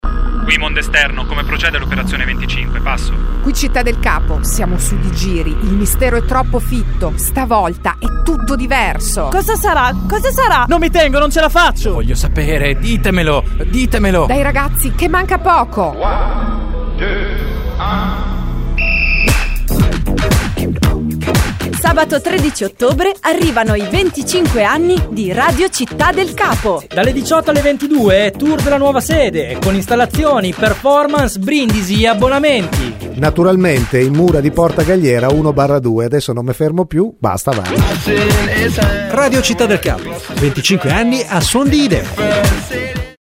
Lo spot